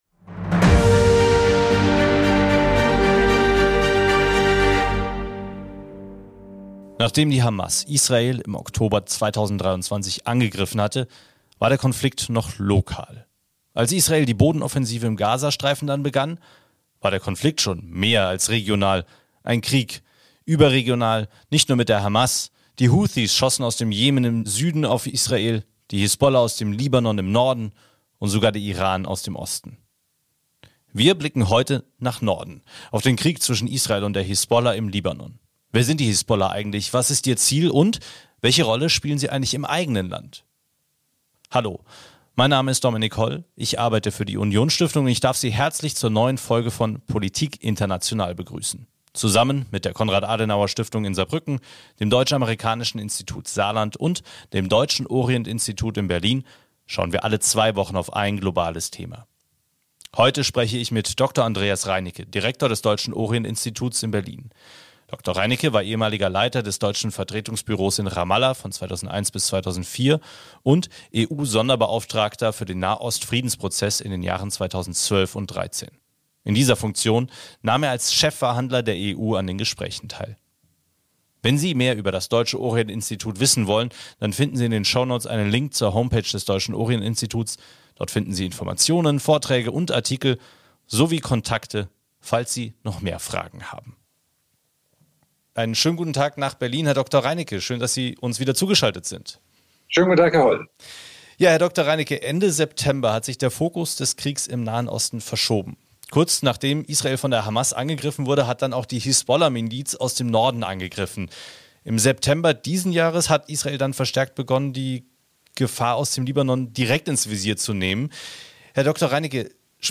Wer sind die Hisbollah und welche Rolle spielt die Miliz im eigenen Land? Ein Gespräch